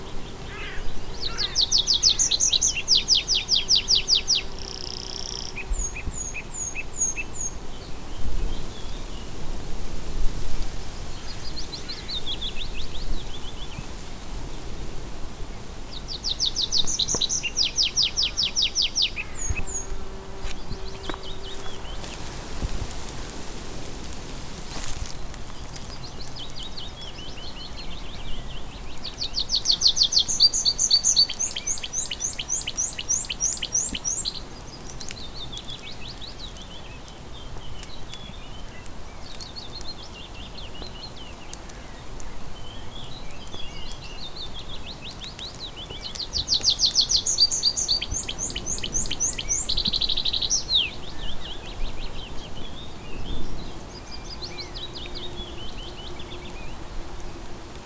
Pipit des arbres – Pelouses calcaires, Lorry-Mardigny
D’abord on entend cet oiseau, puis on le découvre à la cime d’un arbre où il répète son chant avec insistance.
Chant du Pipit des arbres, 10 mai 2024